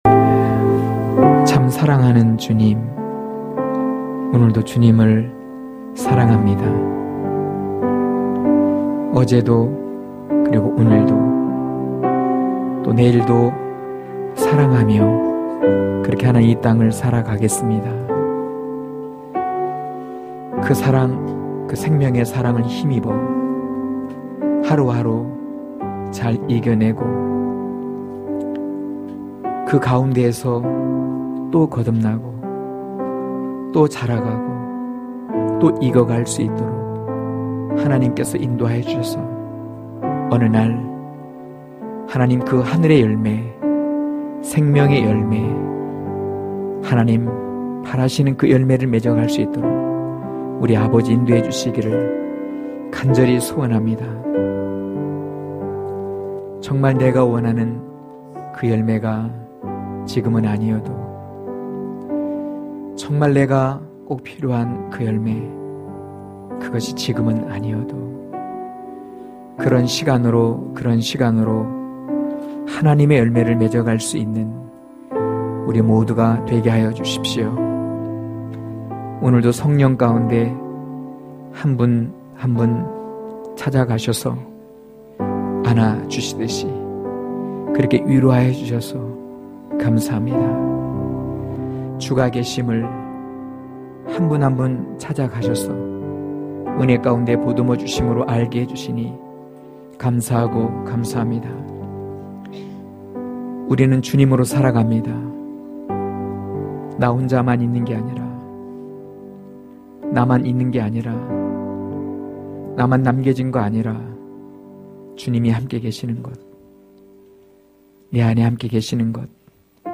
강해설교 - 9.예수로 이기는 길(요일5장4-12절).